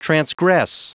Prononciation du mot transgress en anglais (fichier audio)
Prononciation du mot : transgress